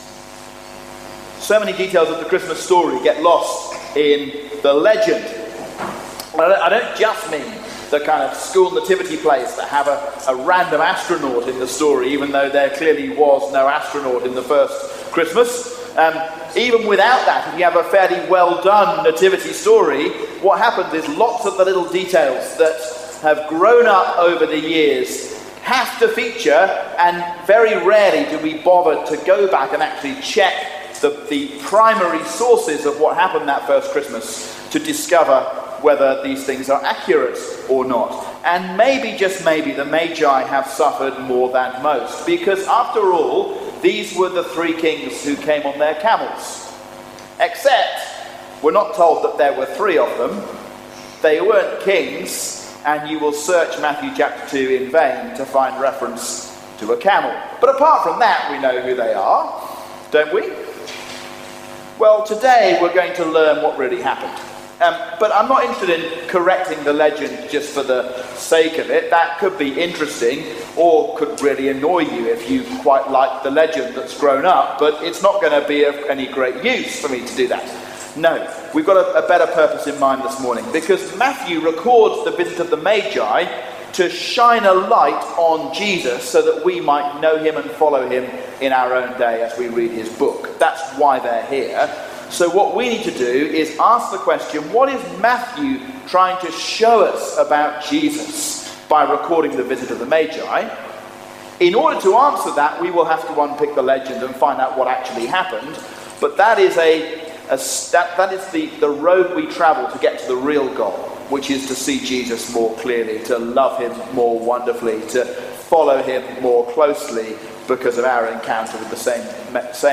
The Newborn King, A sermon on Matthew 2:1-12